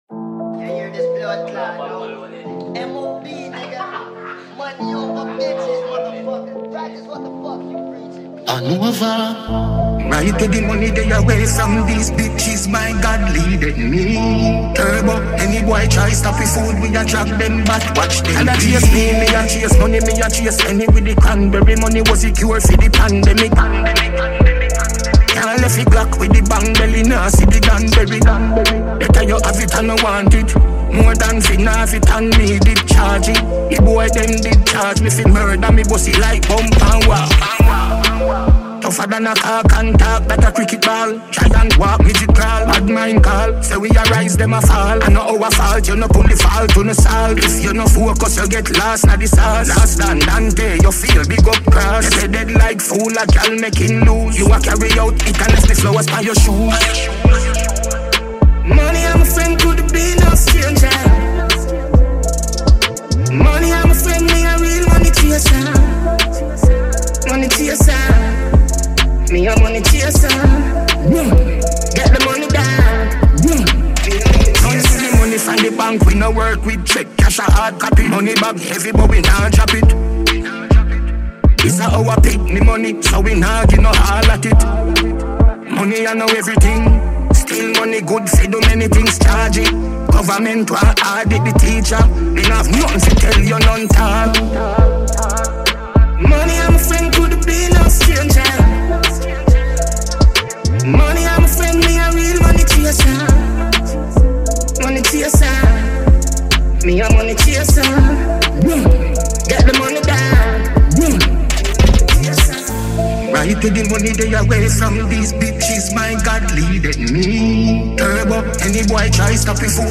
Award winning Jamaican dancehall artiste